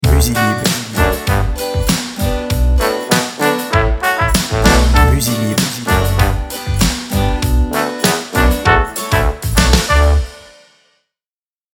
BPM Moyen